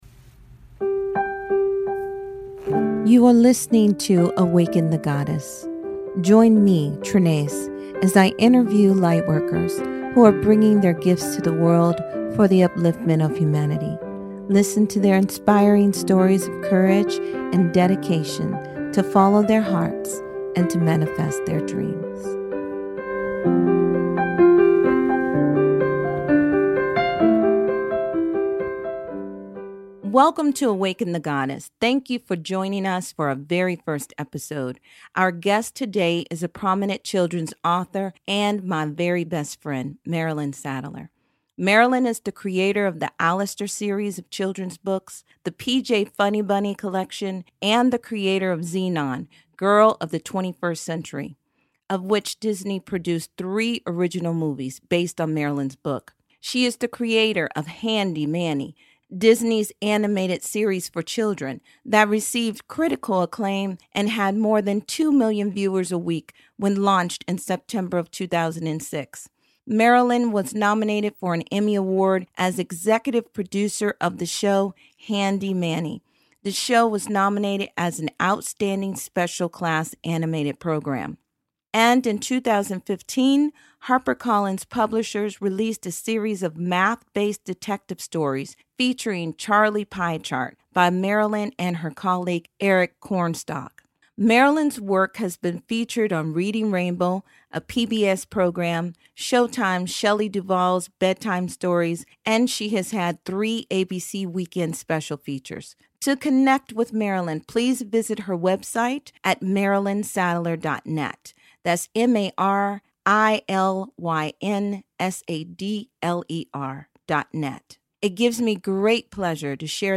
This interview will inspire you in so many ways! It's a refreshing discussion on creativity and spirituality.